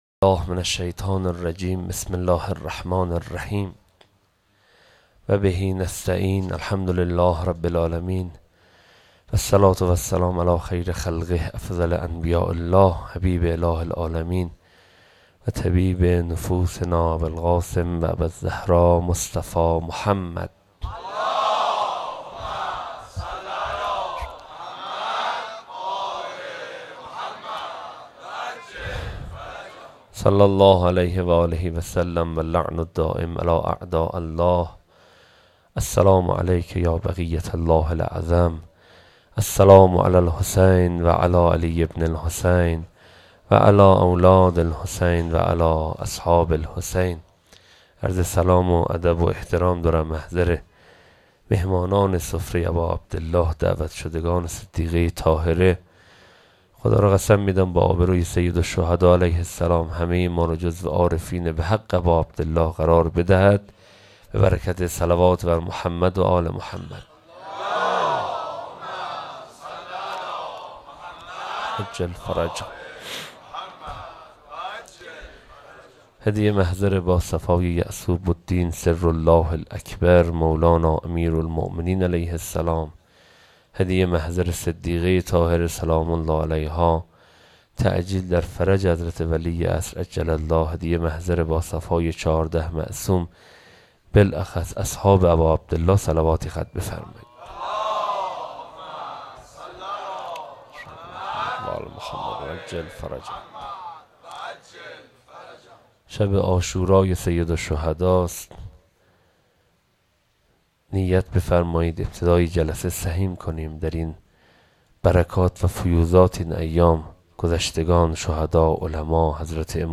1_فایل سخنرانی
sokhanrani.wma